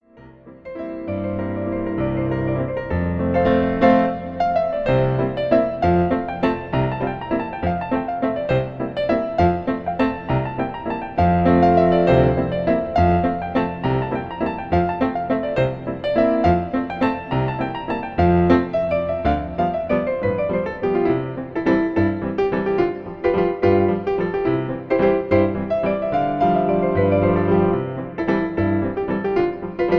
Traditional Lanedlermusic
Piano